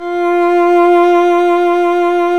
Index of /90_sSampleCDs/Roland - String Master Series/STR_Violin 1 vb/STR_Vln1 _ marc